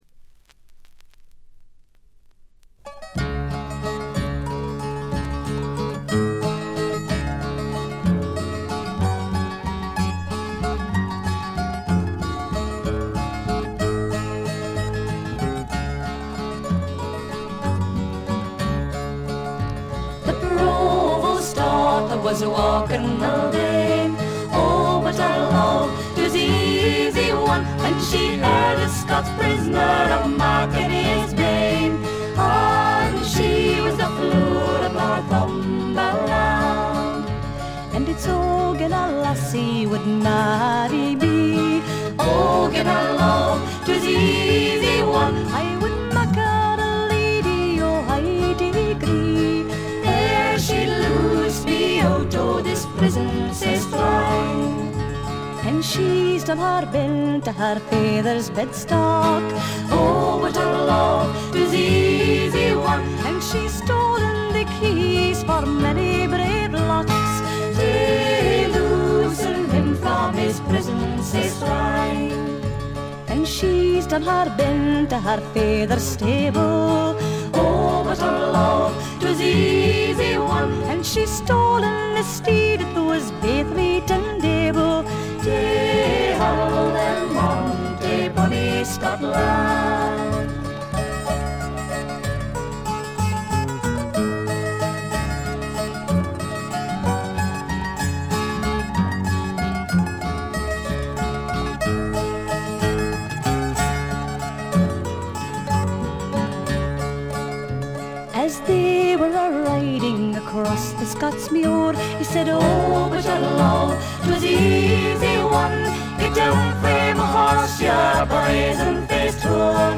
軽微なチリプチ程度。
モダン・フォークの香りただよう美しいコーラスワークが胸を打ちます。
アイリッシュトラッドの基本盤。
試聴曲は現品からの取り込み音源です。